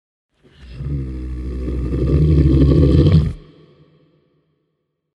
Warning Growl
Category: Sound FX   Right: Personal
Tags: alien fx sound fx monster dnd